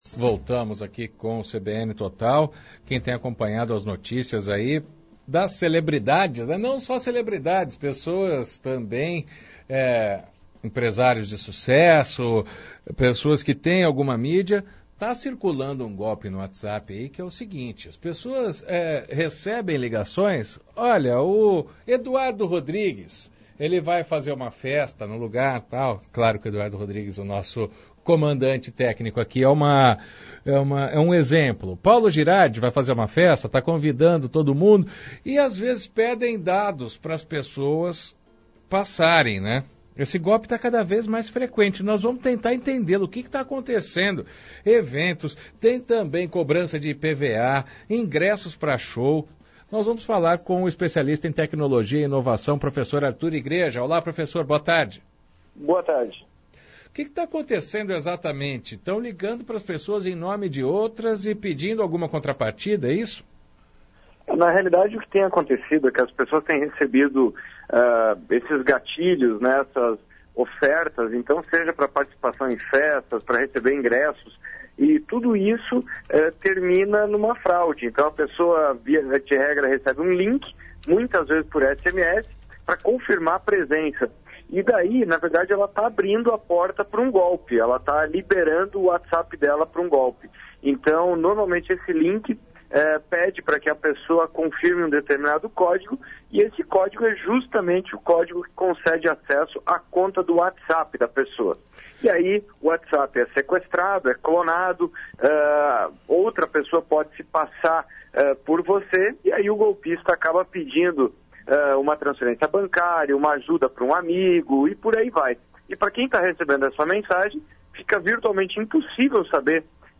Especialista em tecnologia e inovação